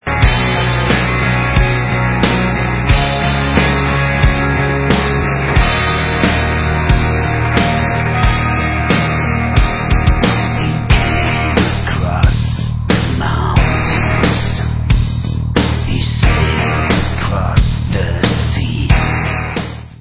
rocková kapela
studiové album